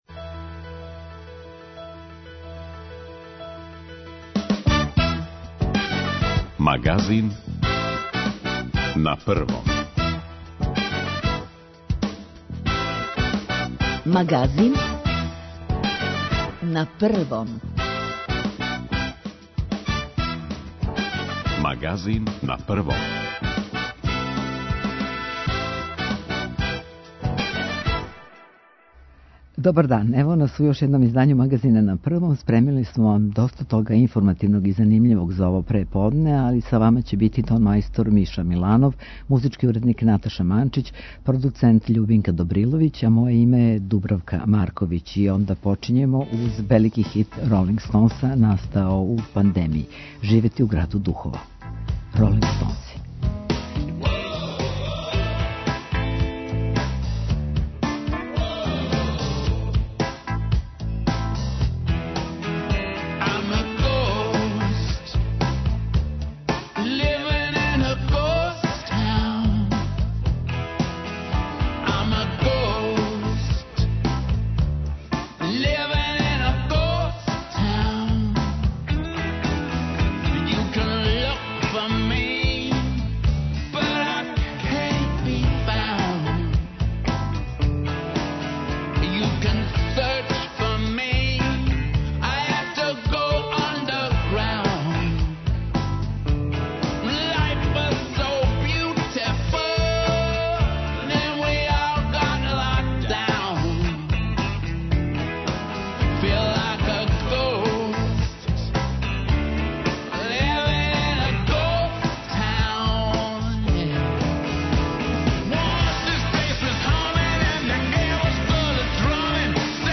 О свему томе за Магазин на Првом говориће председница Владе Ана Брнабић.